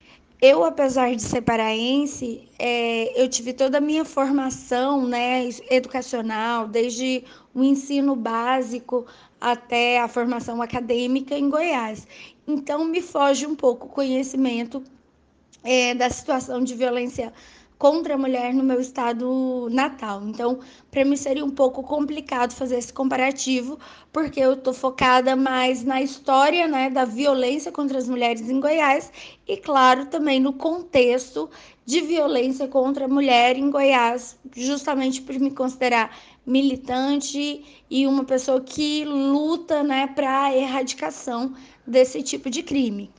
Historiadora